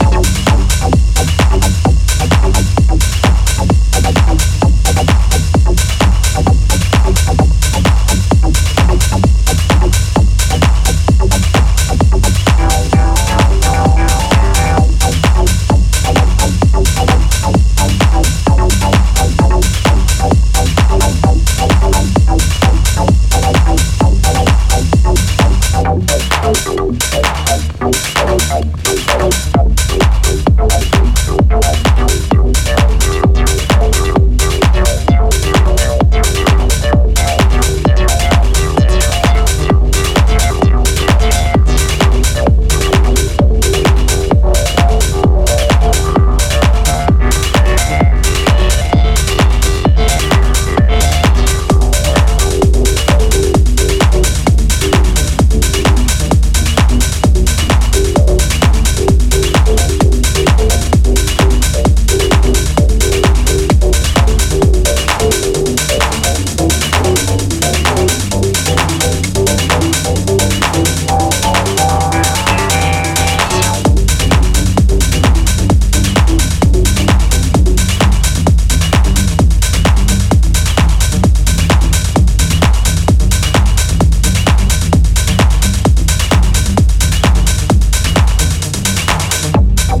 ジャンル(スタイル) TECHNO